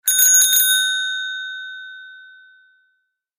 دانلود آهنگ دوچرخه 7 از افکت صوتی حمل و نقل
دانلود صدای دوچرخه 7 از ساعد نیوز با لینک مستقیم و کیفیت بالا
جلوه های صوتی